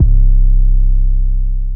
808 (Metro)_5.wav